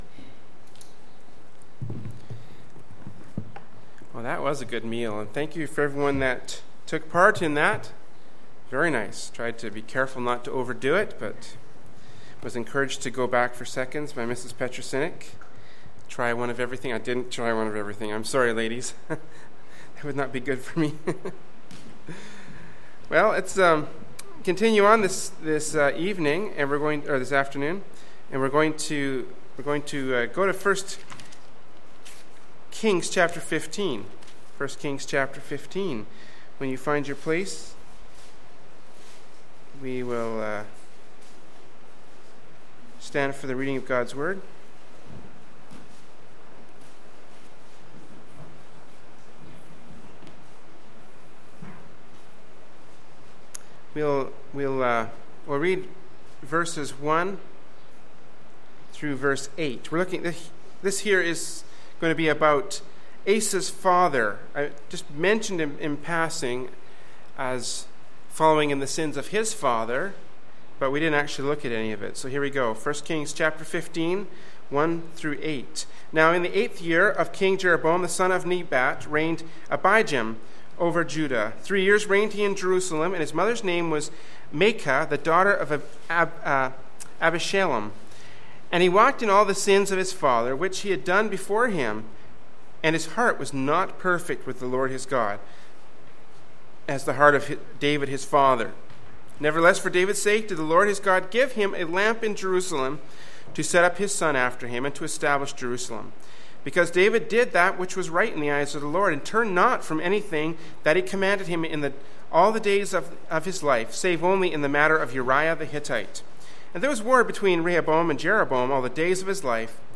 Kamloops, B.C. Canada
Sunday Afternoon Service